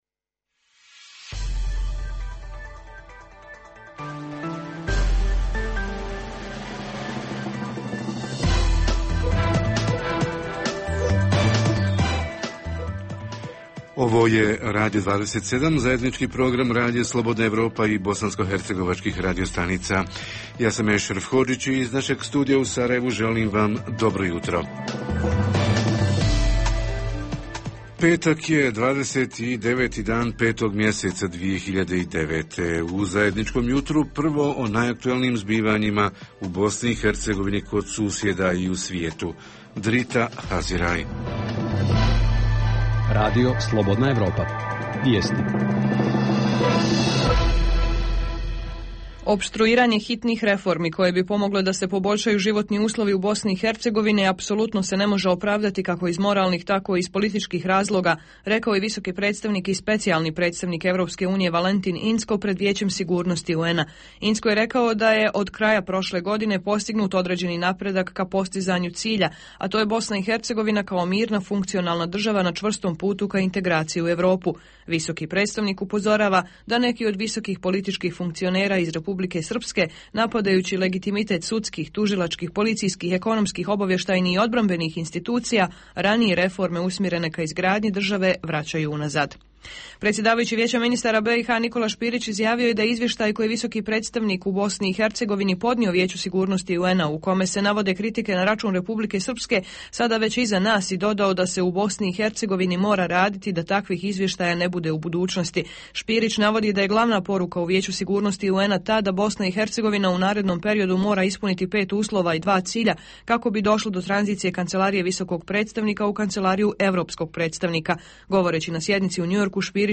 Jutarnji program za BiH koji se emituje uživo. U ovoj emisiji tema su nam bosansko-hercegovački gradovi i njihova arhitektura – kako sačuvati prepoznatljivu autentičnost? Reporteri iz cijele BiH javljaju o najaktuelnijim događajima u njihovim sredinama.
Redovni sadržaji jutarnjeg programa za BiH su i vijesti i muzika.